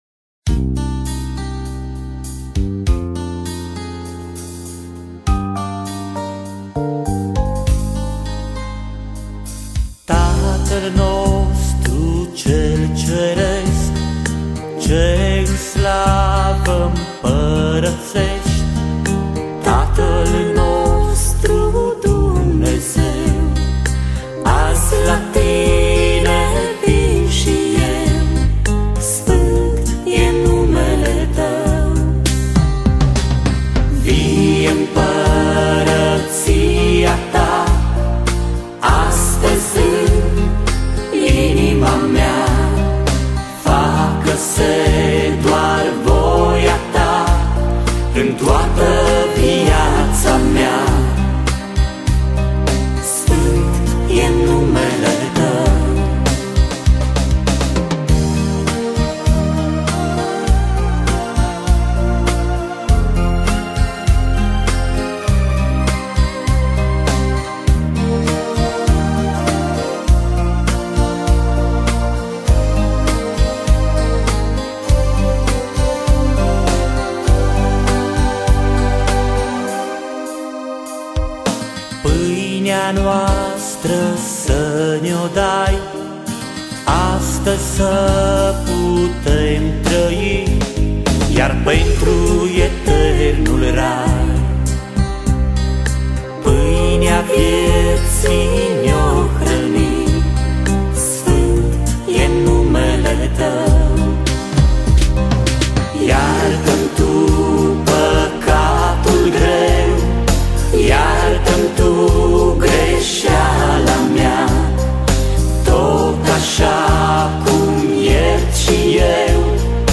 Data: 02.10.2024  Muzica Crestina Hits: 0